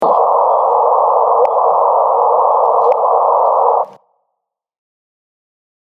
Audio: The “chirp” of gravitational waves recorded by the LIGO team.
GravityChirp.mp3